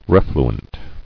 [ref·lu·ent]